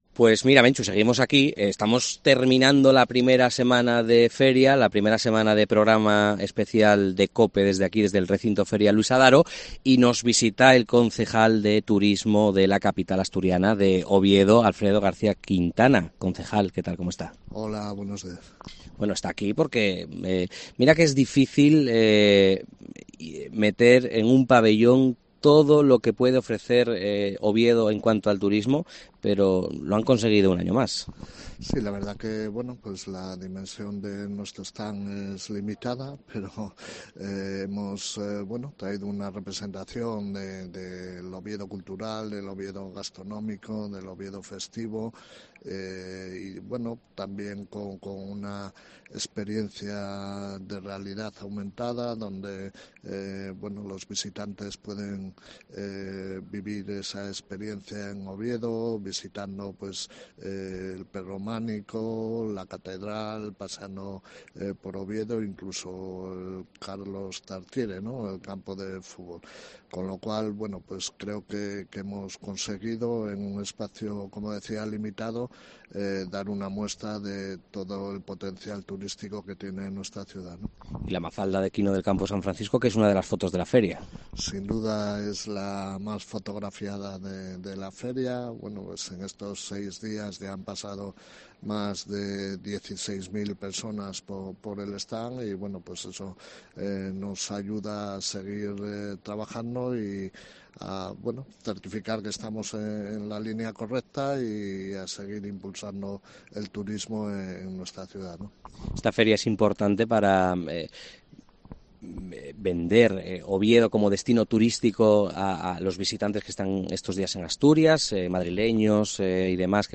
En el especial de COPE desde el Recinto Ferial Luis Adaro, hemos hablado con el concejal de Turismo de la capital asturiana, Alfredo García Quintana
FIDMA 2023: entrevista a Alfredo García Quintana, concejal de Turismo de Oviedo